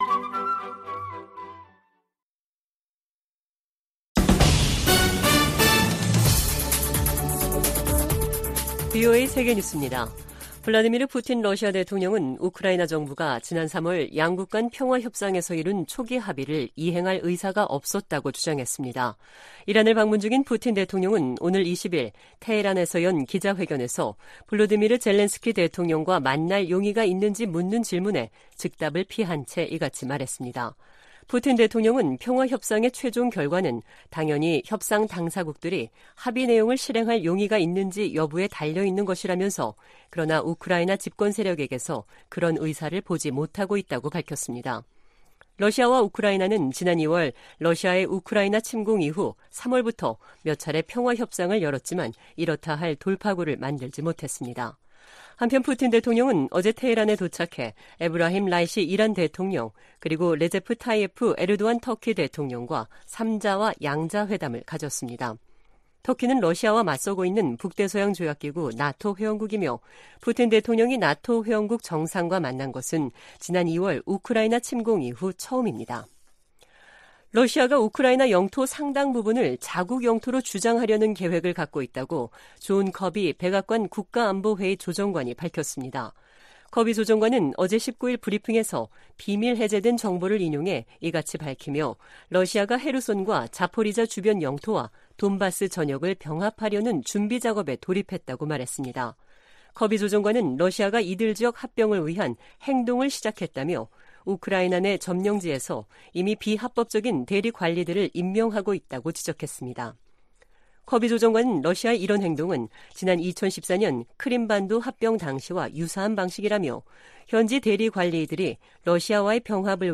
VOA 한국어 간판 뉴스 프로그램 '뉴스 투데이', 2022년 7월 20일 2부 방송입니다. 미 국무부 ‘2022 인신매매 실태 보고서’에서 북한이 20년 연속 최악의 인신매매 국가로 지목됐습니다.